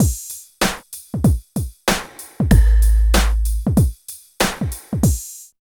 90 DRUM LP-L.wav